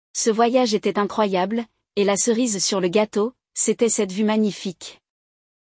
Esse episódio traz um diálogo envolvente entre duas amigas, onde uma delas compartilha detalhes sobre sua última viagem e o que mais a marcou nessa experiência.
É uma série de aulas em áudio para quem quer aprender francês de forma dinâmica, praticando a escuta e a pronúncia.
Neste episódio, você aprenderá a falar sobre viagens em francês, ouvindo uma conversa natural entre duas amigas.